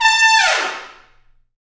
Index of /90_sSampleCDs/Roland L-CDX-03 Disk 2/BRS_Tpts FX menu/BRS_Tps Falls